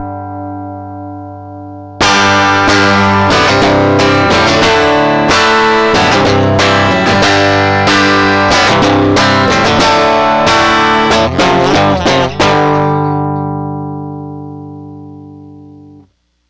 Simulated power tube distortion with a guitar signal (output in